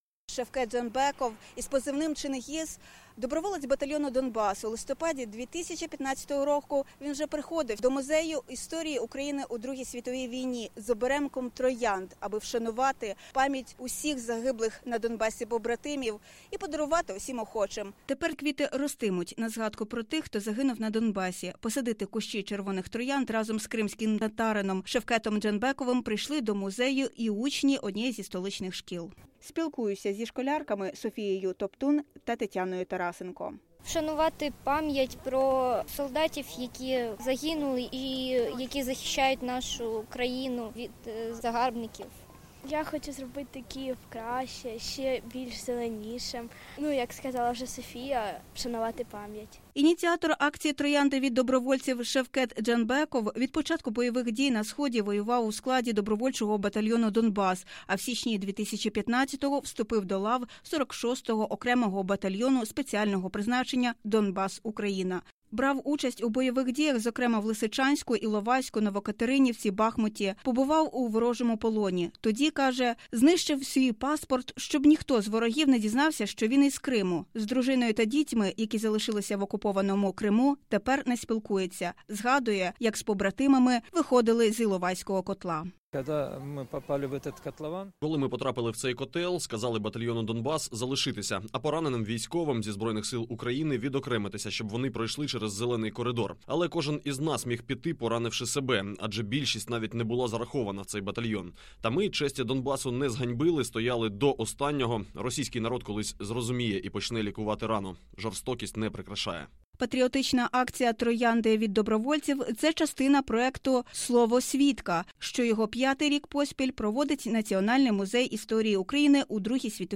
Акція відбулась у Національному музеї історії України у Другій світовій війні.